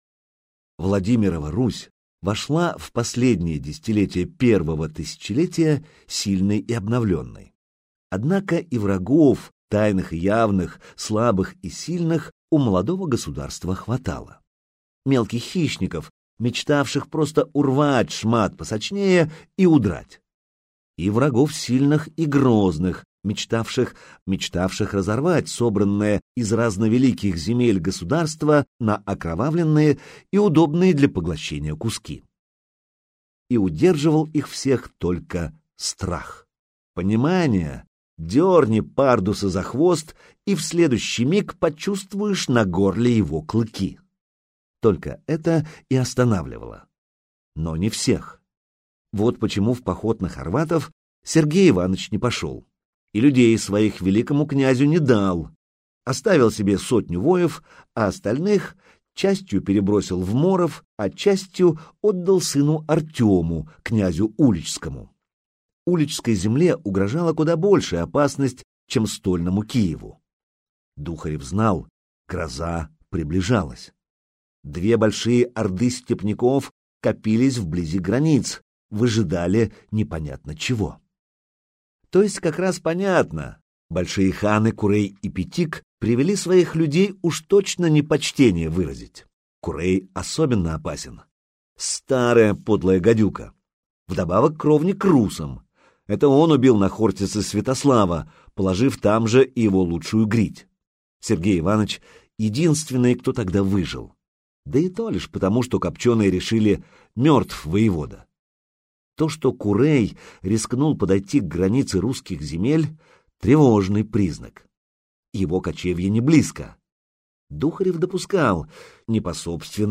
Аудиокнига Доблесть воина - купить, скачать и слушать онлайн | КнигоПоиск